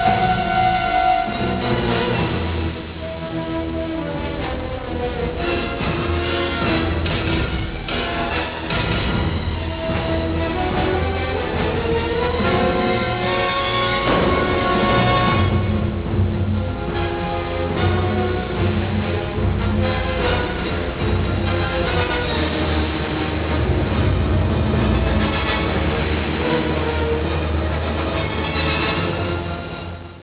Colonna sonora